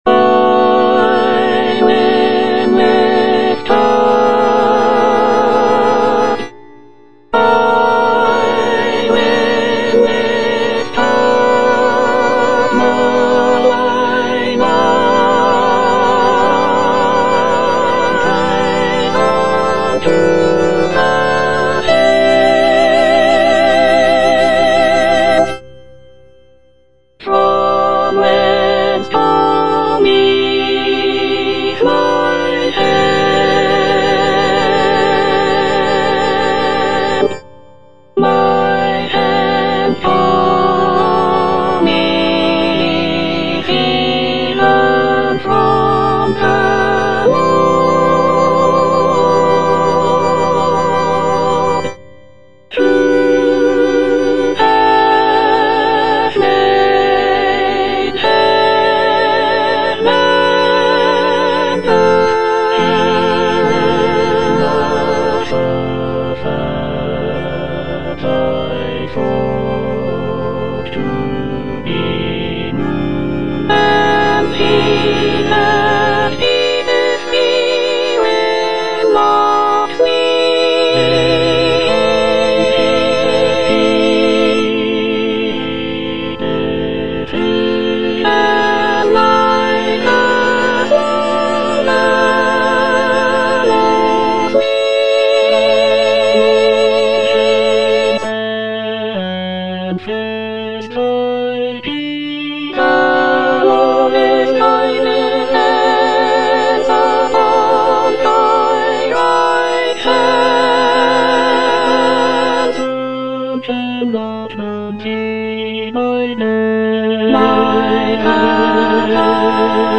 Soprano II (Emphasised voice and other voices)
a choral work